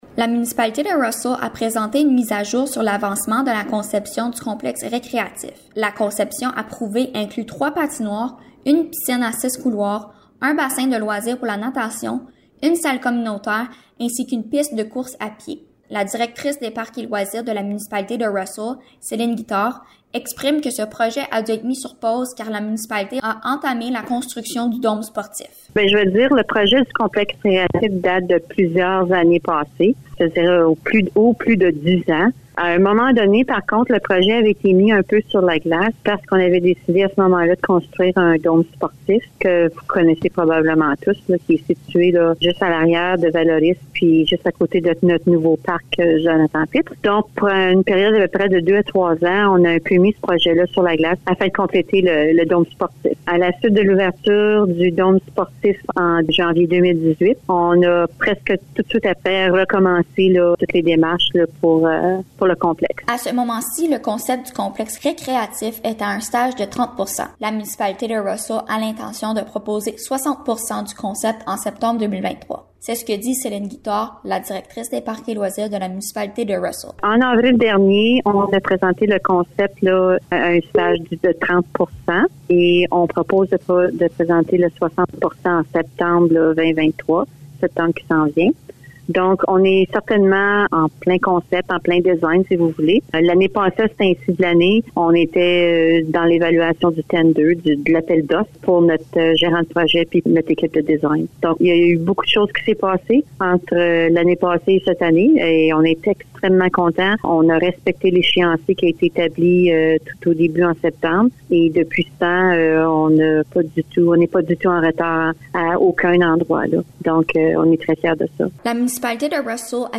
Reportage-complexe-recreatif.mp3